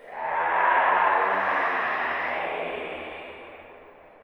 spawners_mobs_mummy_shoot.ogg